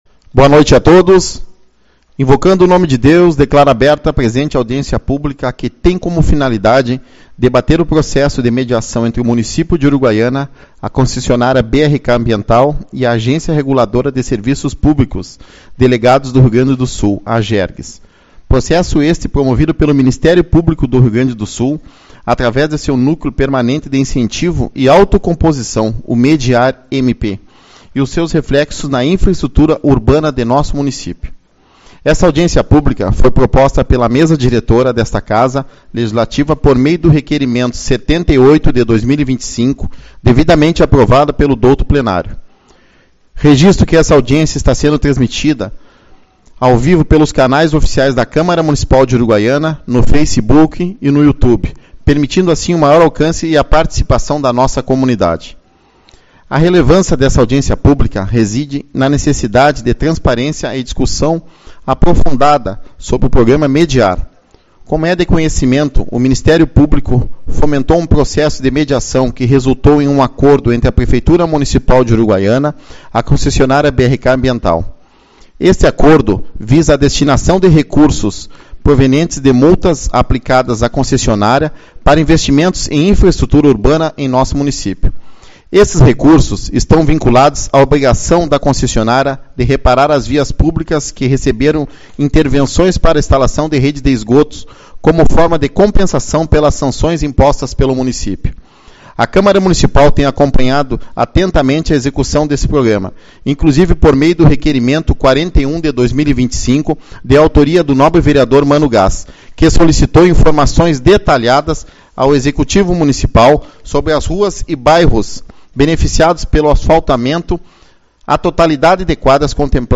26/05 - Audiência Pública-Mediar